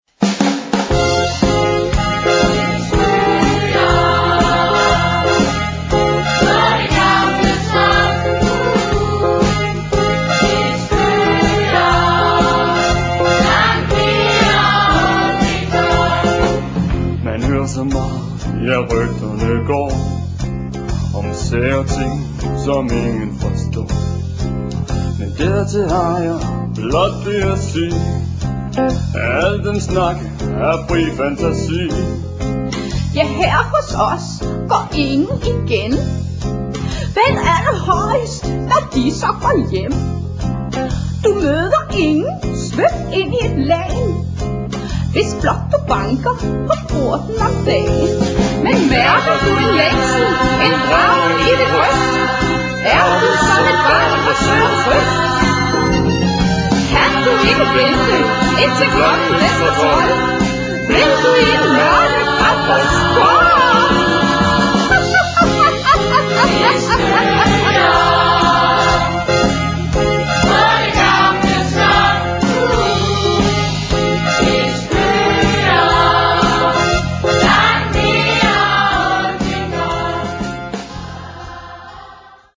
Der er både pop, rock og rumba.